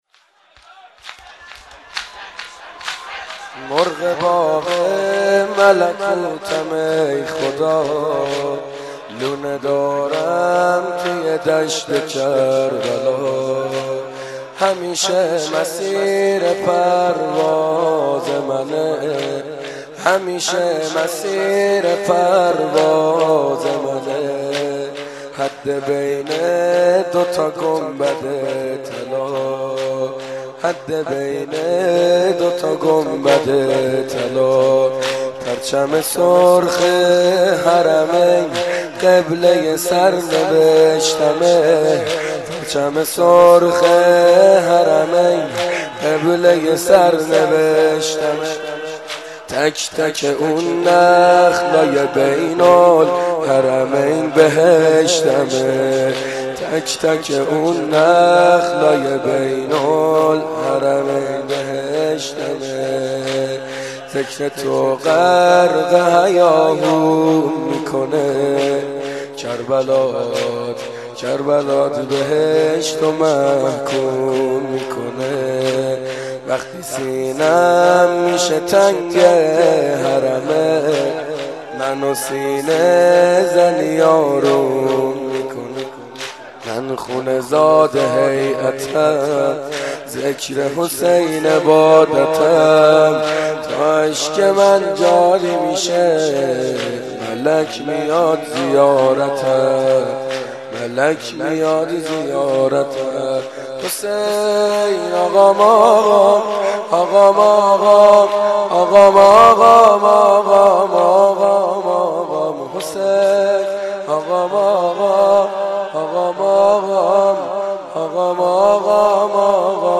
مناسبت : اربعین حسینی
مداح : سعید حدادیان قالب : شور